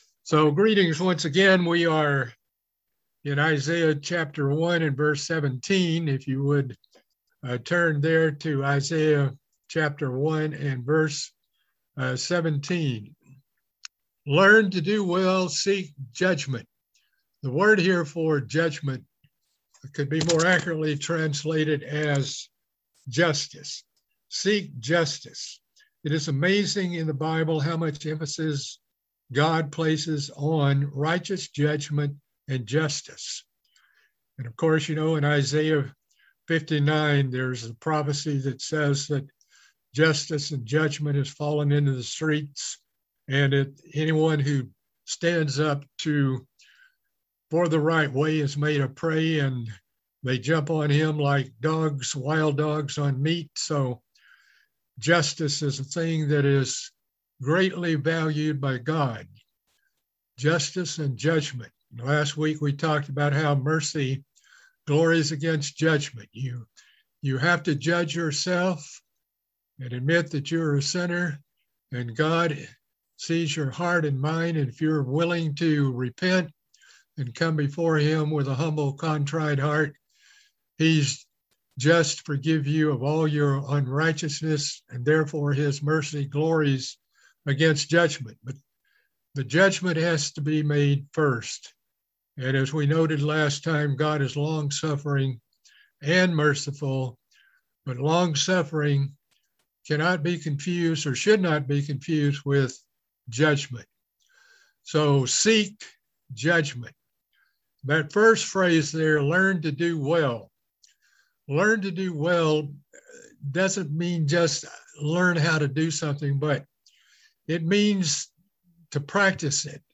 Book of Isaiah Bible Study - Part 3